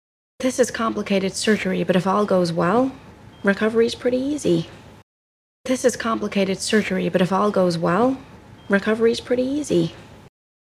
수술을 앞둔 환자와 의사가 나누는 대화입니다.